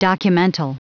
Prononciation du mot documental en anglais (fichier audio)
Prononciation du mot : documental